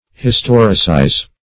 Search Result for " historicize" : The Collaborative International Dictionary of English v.0.48: Historicize \His*tor"i*cize\, v. t. To record or narrate in the manner of a history; to chronicle.